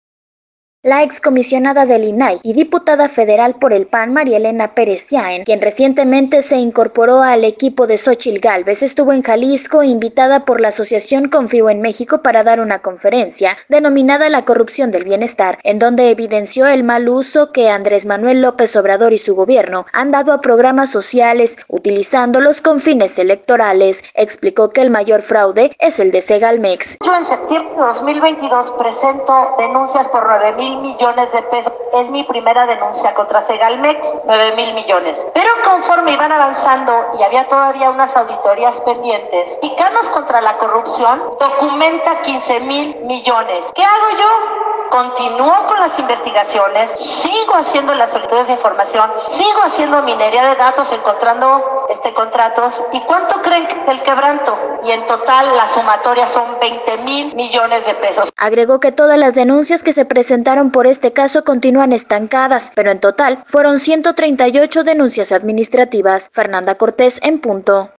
Imparte conferencia magistral María Elena Pérez- jaén